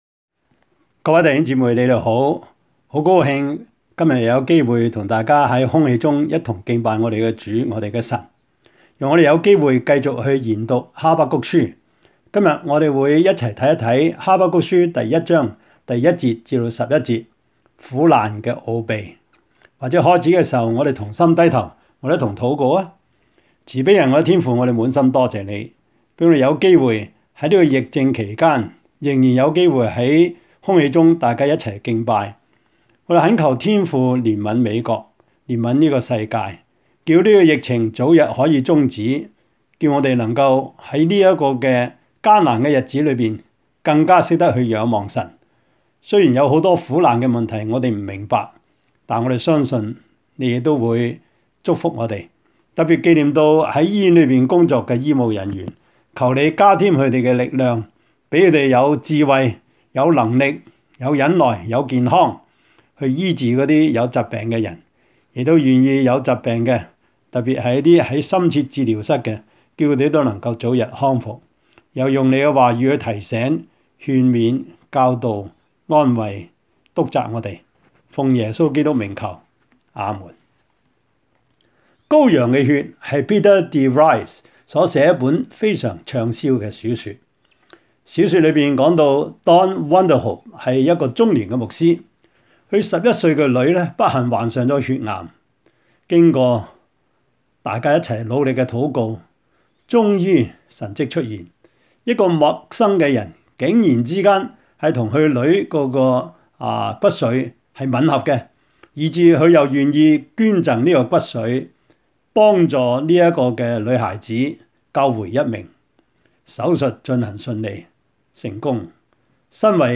Habakkuk-Sermon-2.mp3